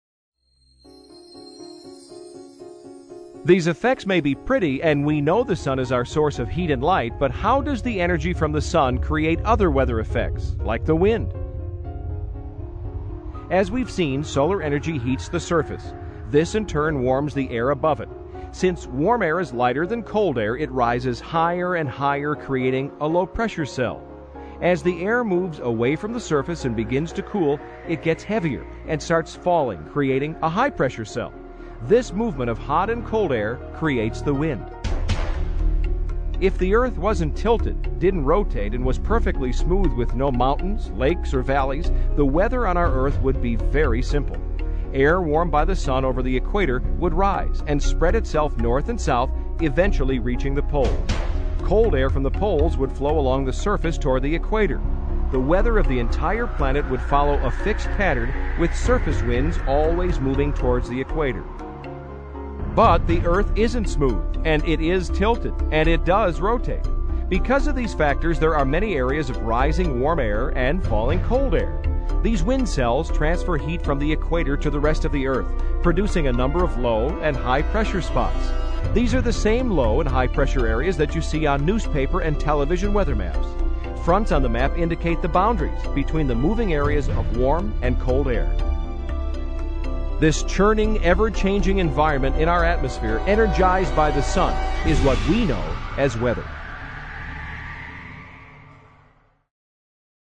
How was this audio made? available in 5.1 surround sound or stereo